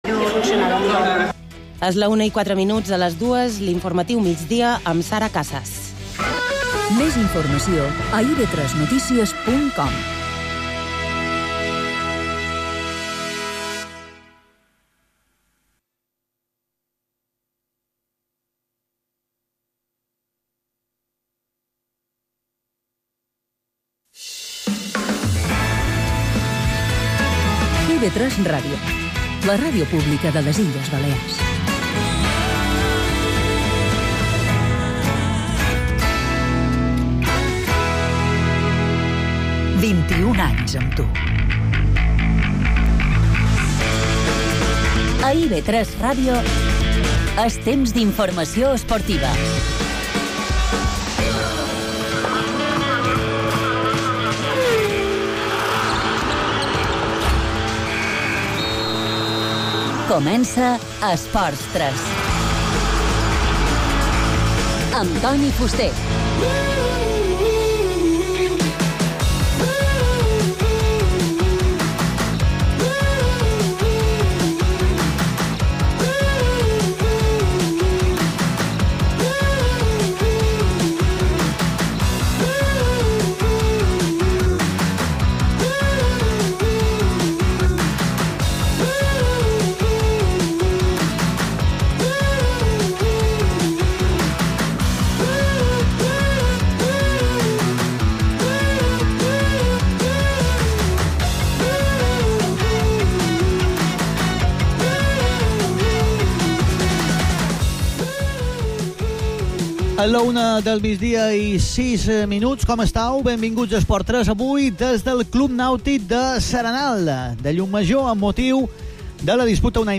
La darrera hora, la millor anàlisi, les tertúlies més plurals i les entrevistes als protagonistes destacats són els ingredients que formen part del programa d’esports de la cadena autonòmica balear.
-Emissió a IB3 Ràdio: de dilluns a divendres de 13 a 14 h.